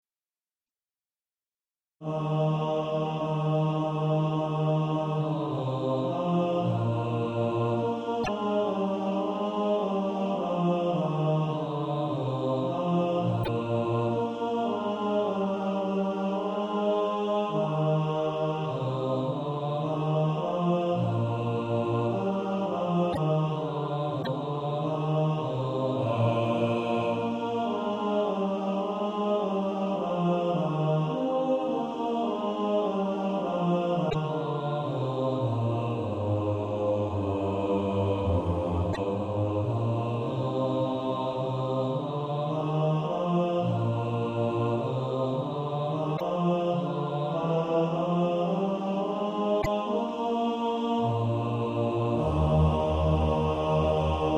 Bass Track.